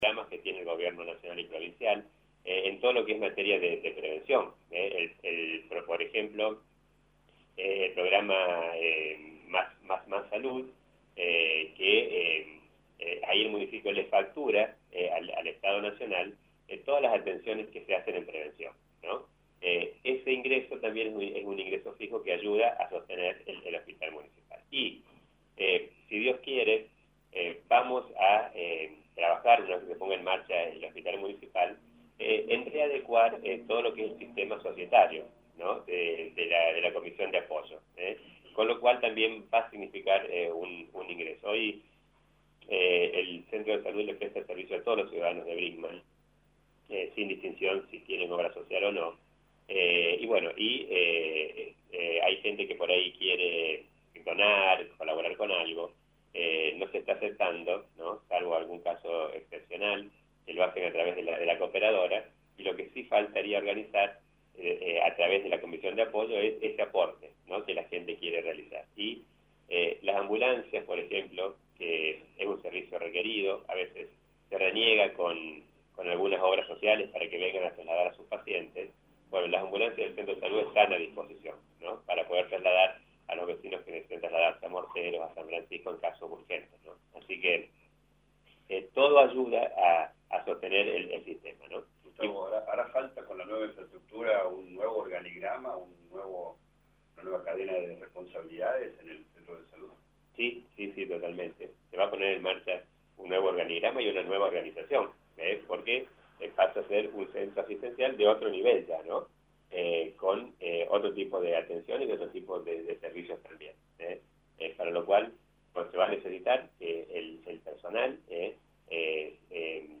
En la mañana del jueves, el intendente Municipal Dr. Gustavo Tevez recibió a los medios de prensa en su despacho para realizar un balance del 2022 y brindar junto a los periodistas por un mejor 2023.